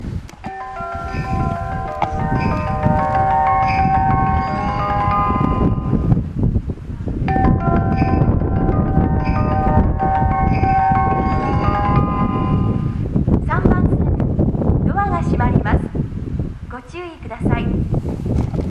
○発車メロディー○
スピーカーは小ボスで音質はとてもいいと思います。
３番線宇都宮線
発車メロディー２コーラスです。２コーラス必ず鳴ります。